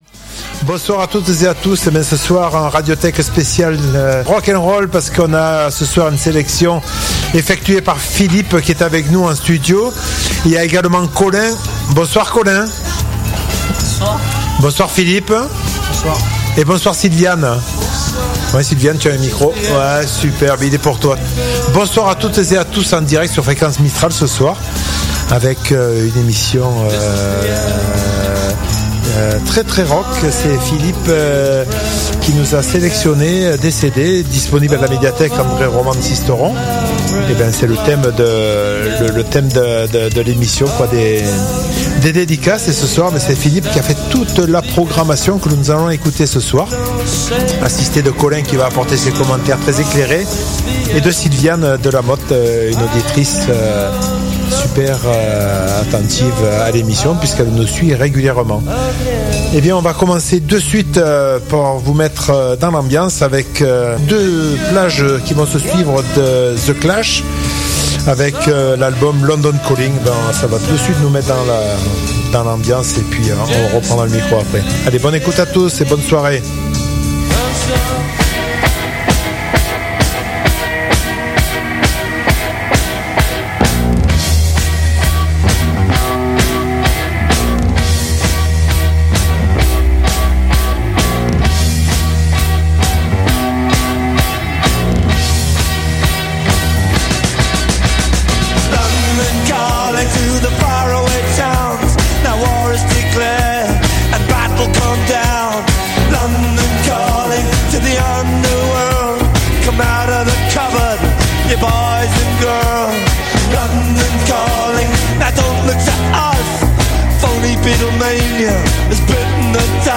Pour cette émission, le rendez-vous incontournable sur le 99.2 branche les guitares pour notre plus grand plaisir !